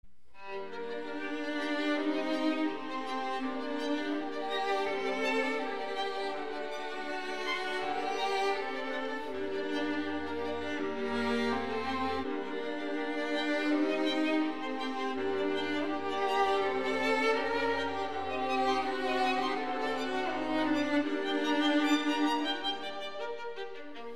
يترأسها التشيلو ويعزف اللحن بشكل غنائي بديع مصحوباً بعزف من الكمان الأول والثاني ولكن بنوتات أسرع مع ارتفاع الصوت قليلاً ليصبح (بيانو p)